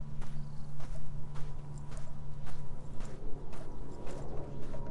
walk for cartoon
描述：imitation walking for a animations and cartoons project
标签： footsteps foot steps feet walk floor walking
声道立体声